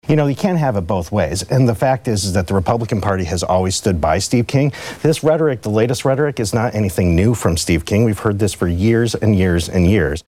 (Radio Iowa) — Republican Congressman Steve King took to the floor of the U.S. House today (Friday) to respond to a New York Times story in which he’s quoted talking about the terms white nationalist, white supremacy and western civilization.